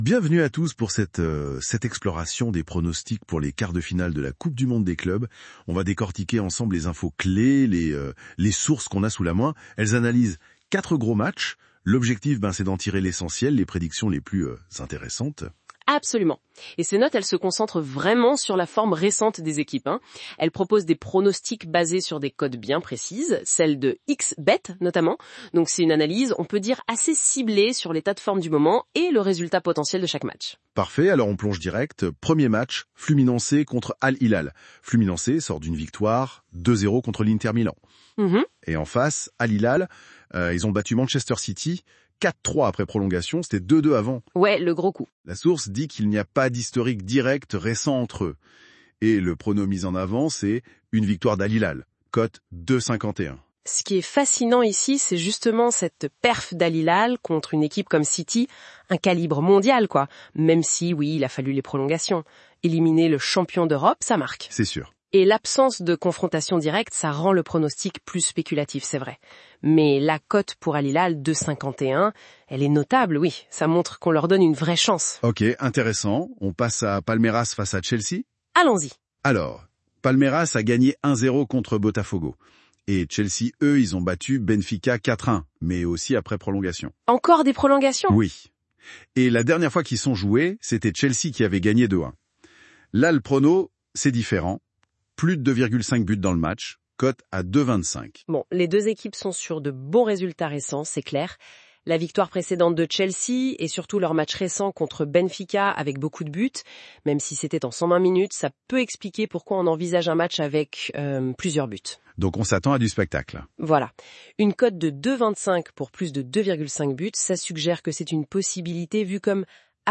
Comme vous pouvez le constater, nos deux experts observent en détail les dernières confrontations entre les équipes ainsi que les derniers résultats obtenus dans leur championnat respectif. Ils prennent aussi en compte les joueurs en forme de chaque côté afin de livrer le meilleur pronostic gagnant avec parfois la cote de l’un de nos meilleurs bookmakers du moment !